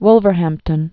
(wlvər-hămptən, -hăm-)